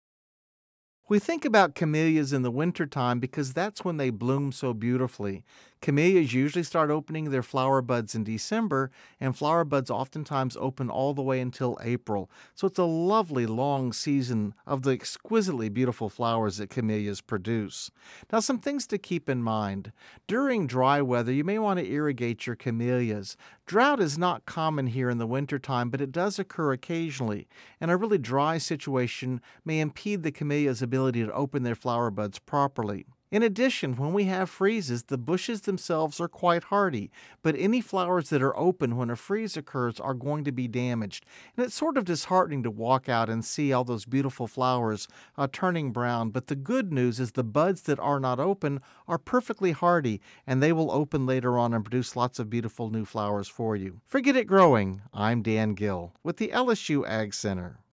(Radio News 12/13/10) Camellias bloom beautifully throughout the winter months. If Louisiana has a dry spell during winter, you may want to irrigate your camellias. Freezes can damage open blooms but generally won't hurt buds.